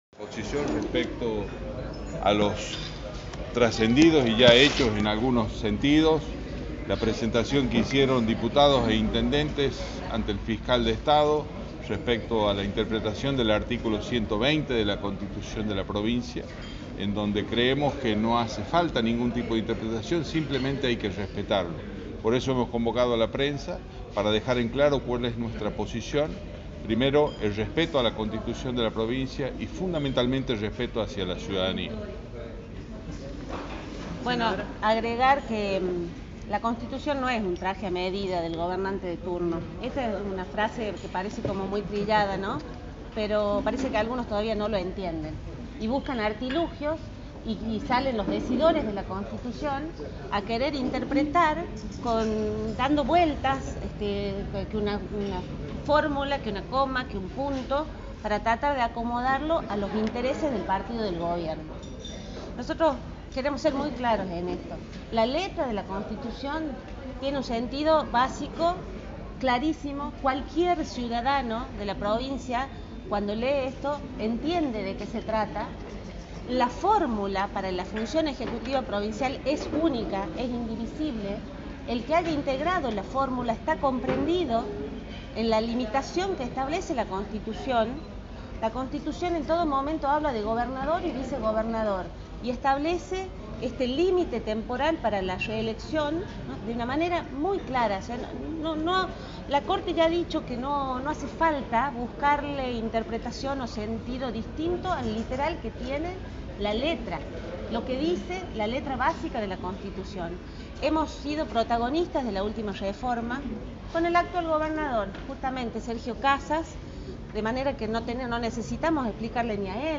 La rueda de prensa